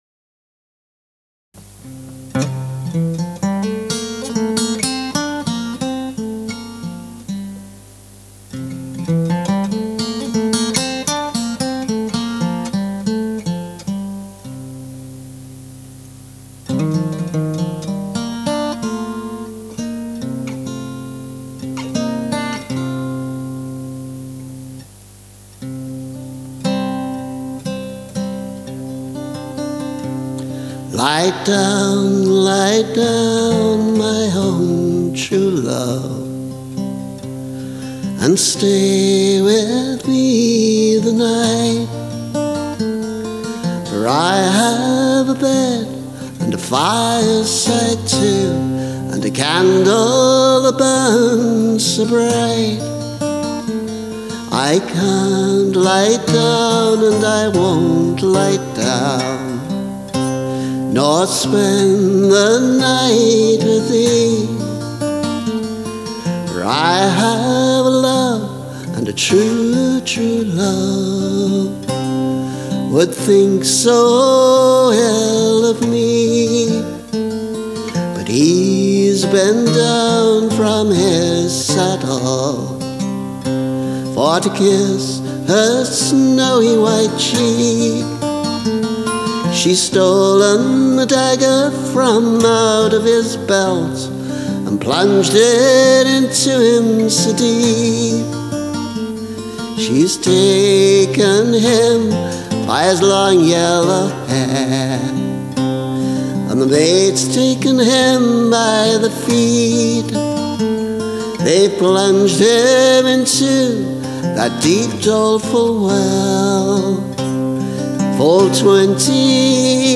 First demo with guitar and voice only.
This is a traditional ballad (Child 68).